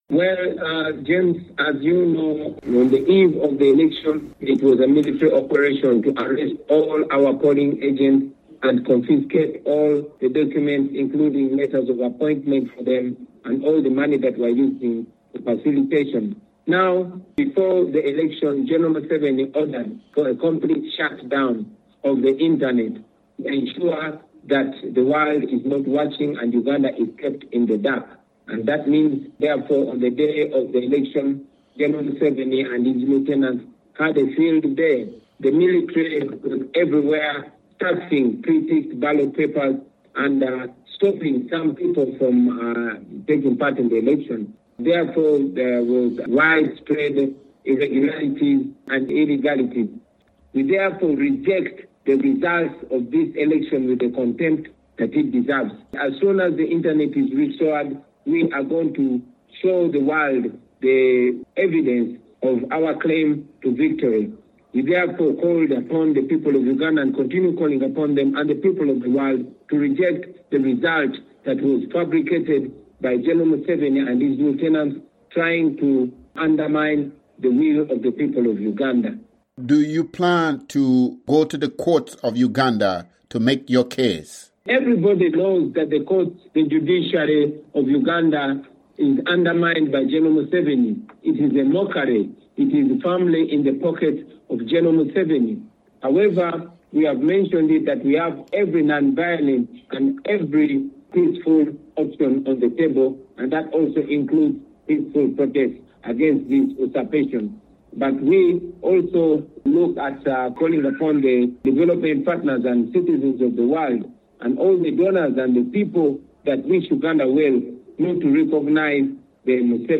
The Uganda opposition presidential candidate spokes to VOA from house arrest